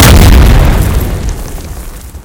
tnt_explode.ogg